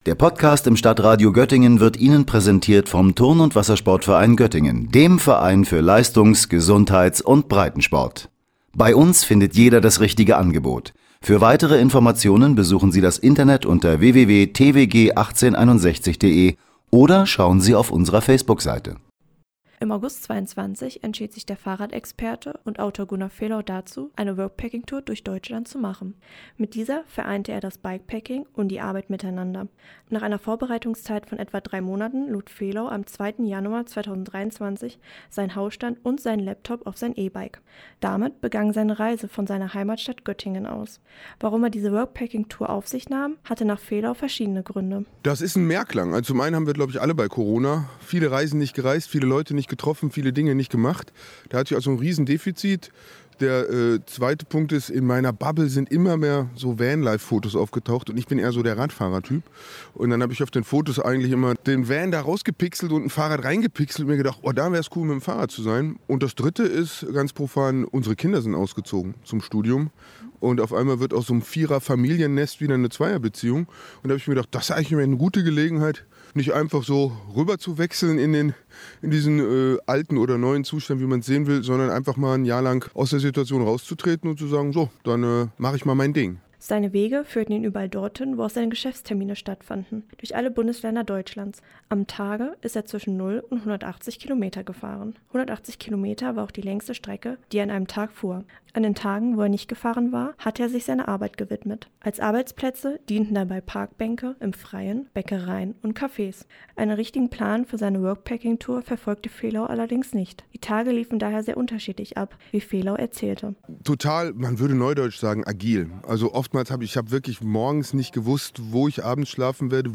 O-Ton 1